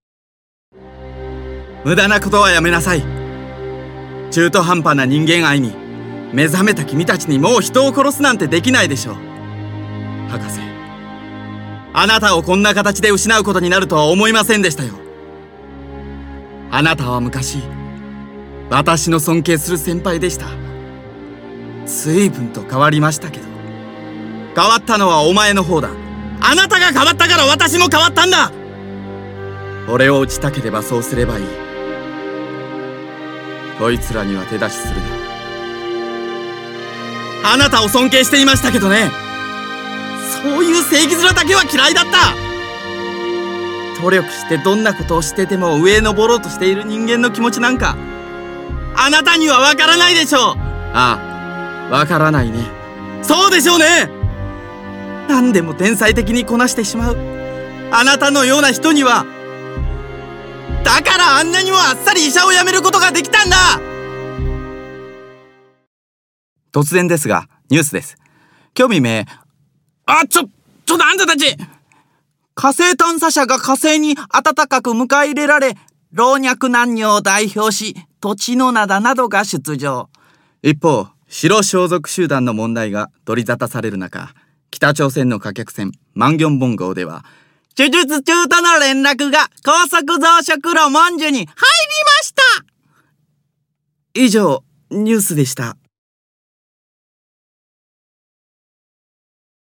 出⾝地・⽅⾔ 徳島県・阿波弁、関西弁
ボイスサンプル
ボイス１